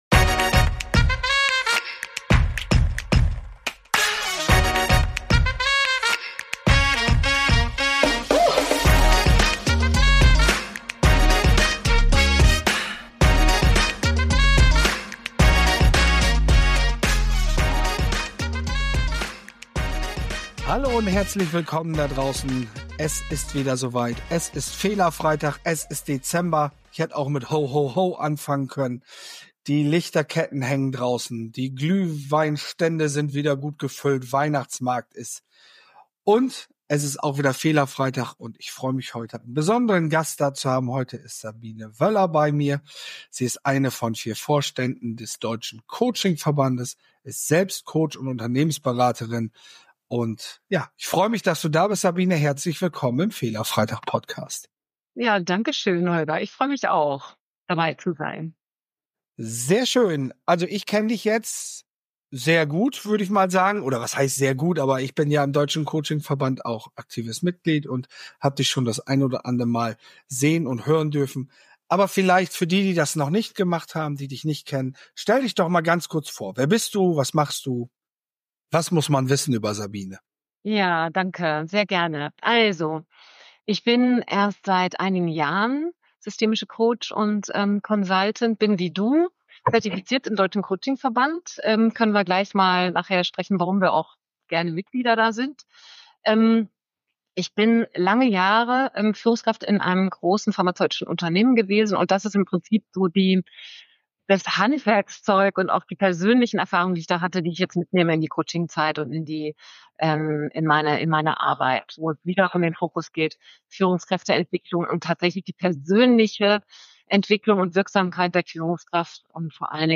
Der Deutsche Coaching Verband im Fokus Einblicke und Impulse – Interview